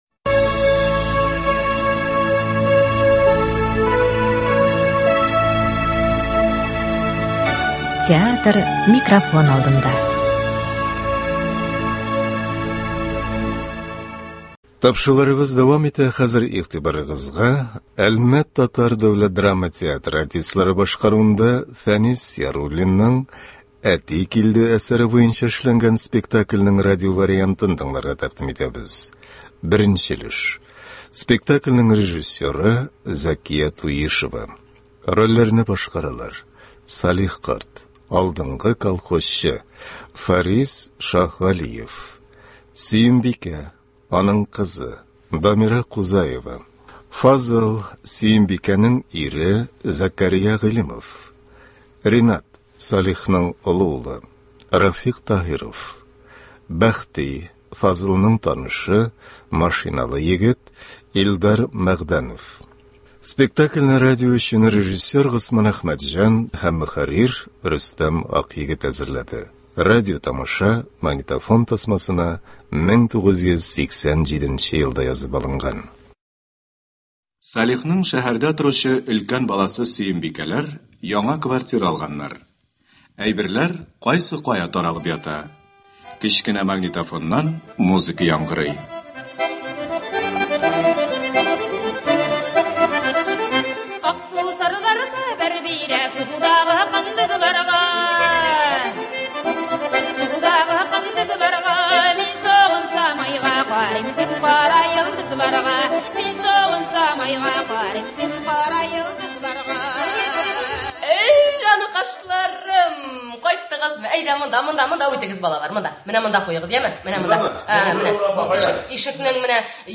Спектакль (29.01.24)
“Әти килде”. Әлмәт татар дәүләт драма театры спектакле.